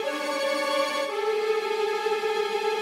Index of /musicradar/gangster-sting-samples/85bpm Loops
GS_Viols_85-DA.wav